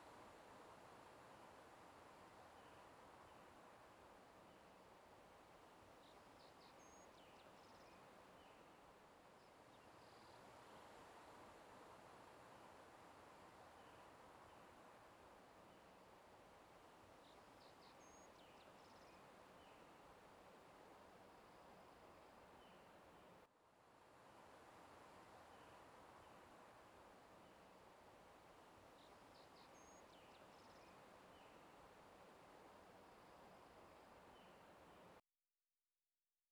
03_书店外黄昏.wav